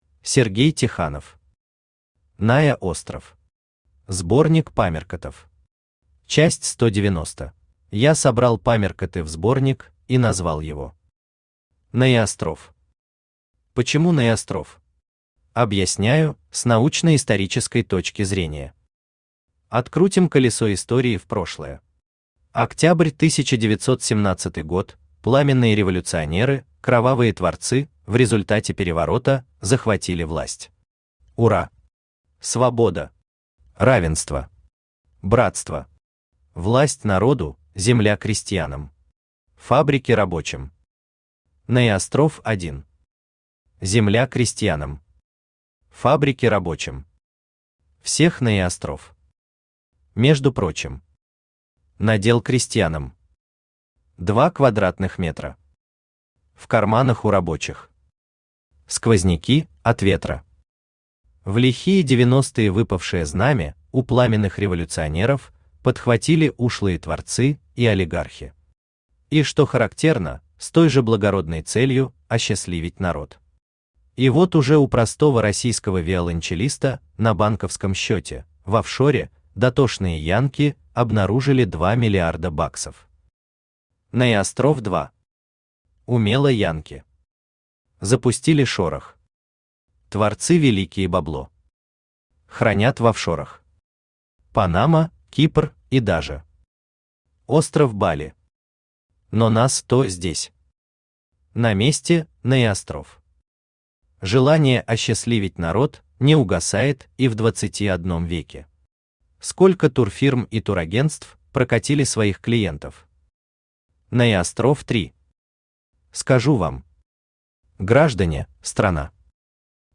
Аудиокнига НаеОстров. Сборник памяркотов. Часть 190 | Библиотека аудиокниг
Читает аудиокнигу Авточтец ЛитРес.